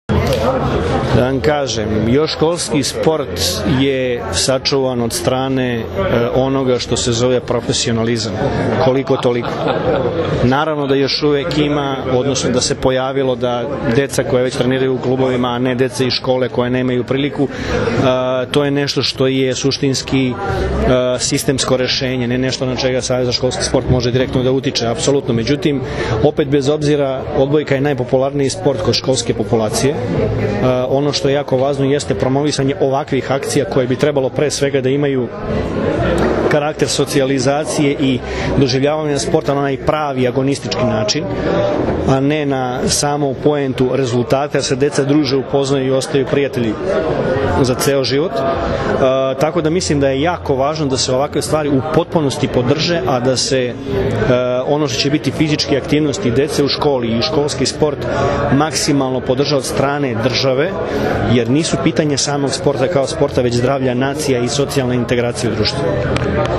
U Palati Srbija danas je obavljen žreb za Svetsko školsko prvenstvo u odbojci, koje će se odigrati od 25. juna – 3. jula u Beogradu.
IZJAVA VLADIMIRA GRBIĆA